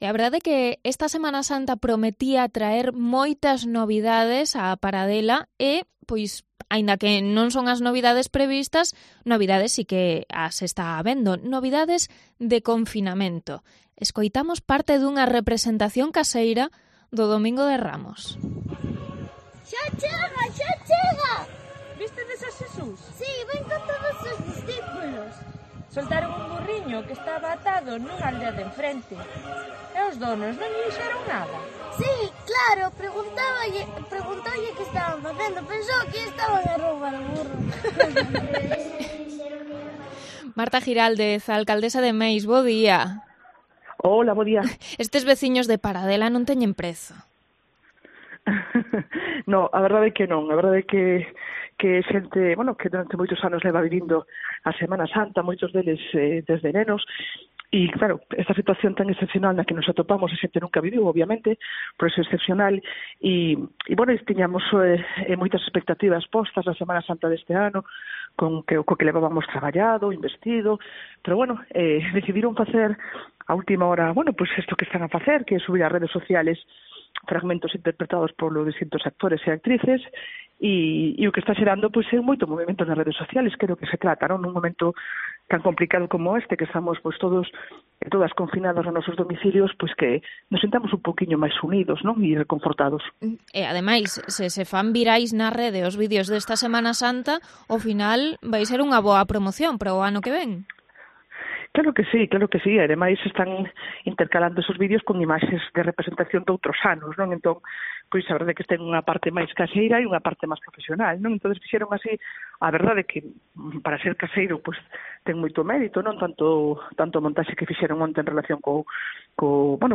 AUDIO: Entrevista a la alcaldesa de Meis sobre la Semana Santa de Paradela